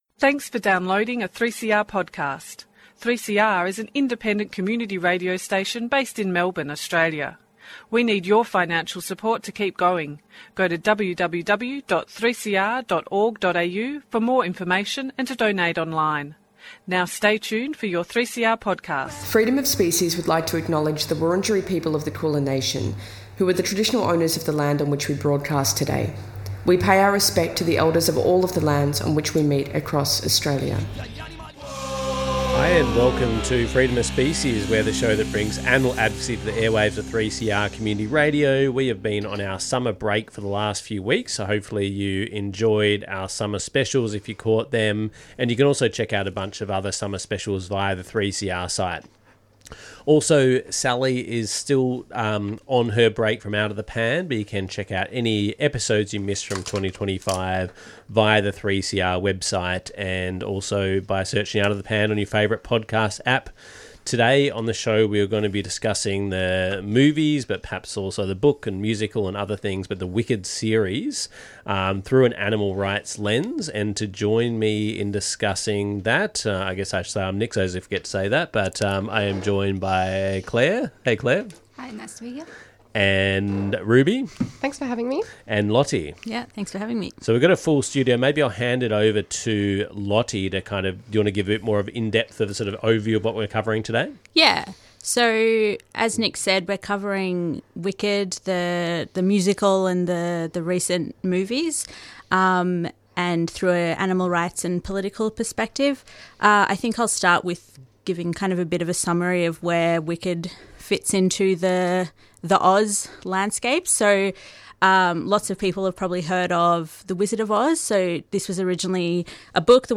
Tweet Freedom of Species Sunday 1:00pm to 2:00pm Animal advocacy on the airwaves, hosted by a team of local animal advocates.